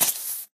creeper2.ogg